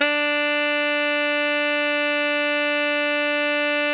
TENOR FF-D4.wav